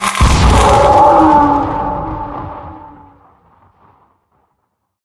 dc0f4c9042 Divergent / mods / Soundscape Overhaul / gamedata / sounds / monsters / poltergeist / tele_death_1.ogg 40 KiB (Stored with Git LFS) Raw History